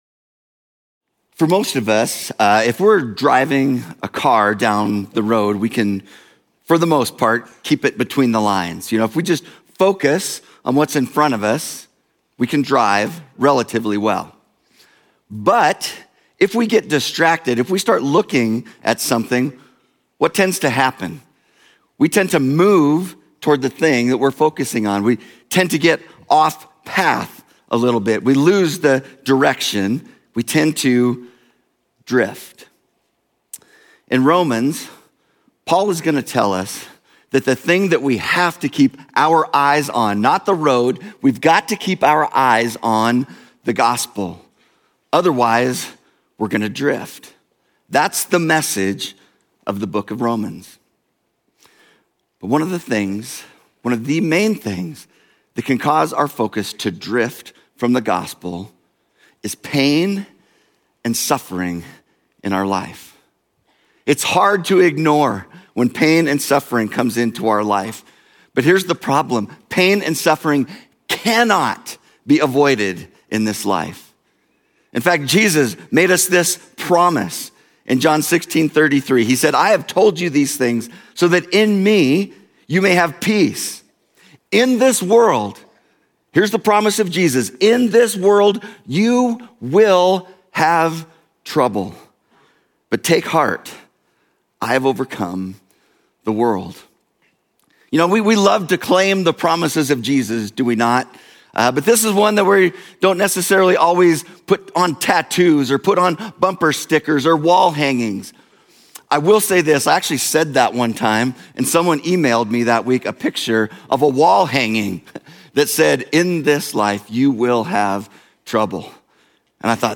Journey Church Bozeman Sermons Book of Romans: The Gospel In Suffering Feb 23 2025 | 00:41:02 1x 00:00 / 00:41:02 Subscribe Share Apple Podcasts Overcast RSS Feed Share Link Embed